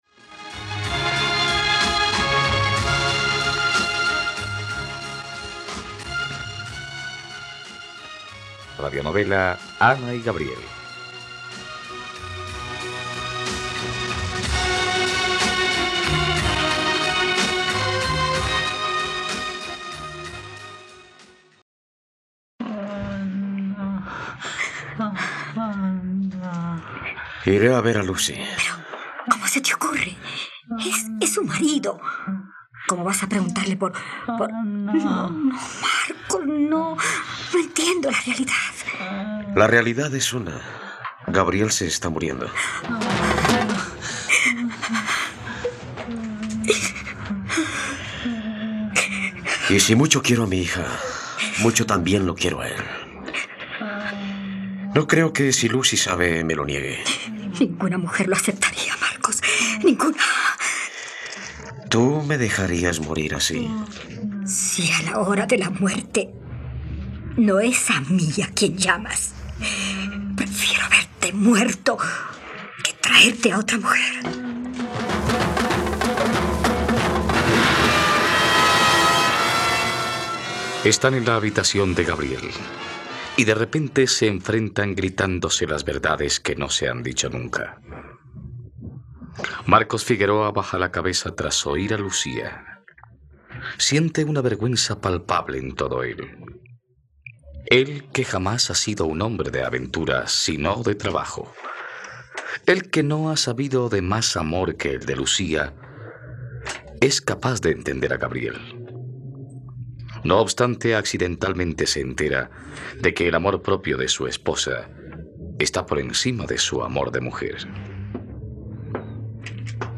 ..Radionovela. Escucha ahora el capítulo 101 de la historia de amor de Ana y Gabriel en la plataforma de streaming de los colombianos: RTVCPlay.